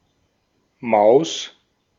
Ääntäminen
Ääntäminen Tuntematon aksentti: IPA: /maʊ̯s/ Haettu sana löytyi näillä lähdekielillä: saksa Käännös Konteksti Substantiivit 1. hiiri tietotekniikka Artikkeli: die .